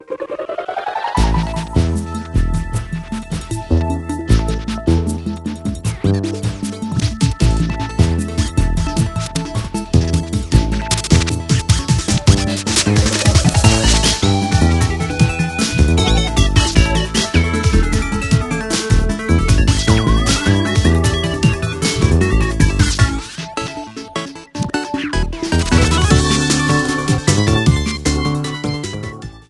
A song
Ripped from the game